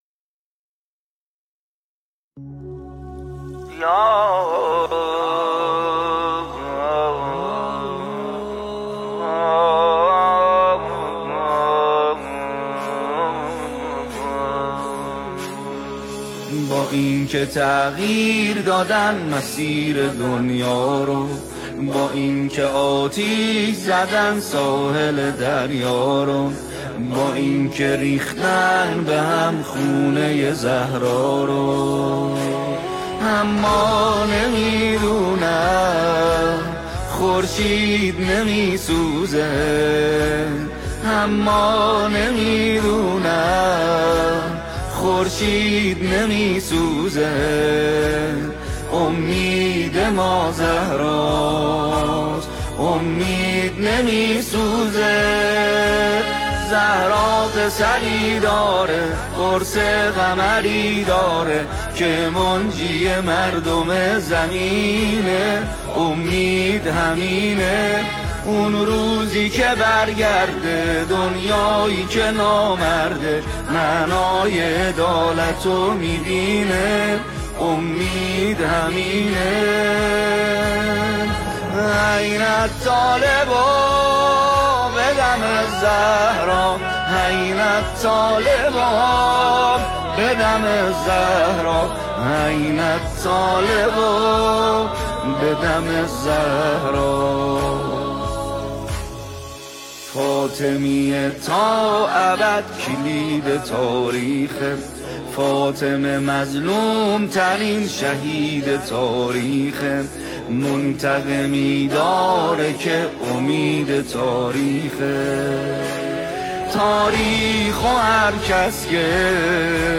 به گزارش خبرگزاری مهر، عصر روز جمعه ۳۰ آبان ۱۴۰۴ اجتماع بزرگ نوگلان و نوجوانان فاطمی با حضور ۲ هزار تن از نوگلان و نوجوانان فاطمی در آستان مقدس امام‌زاده صالح تجریش برگزار شد.
در این اجتماع بزرگ، سرود «خورشید نمی‌سوزد» با مداحیِ محمدحسین پویانفر رونمایی و اجرا شد.
مداحی محمدحسین پویانفر و هم‌خوانی ۲ هزار نوجوان در «خورشید نمی‌سوزد»
پویانفر در این مراسم سرود «خورشید نمی‌سوزد» را با نوگلان و نوجوانان فاطمی هم‌خوانی کرد.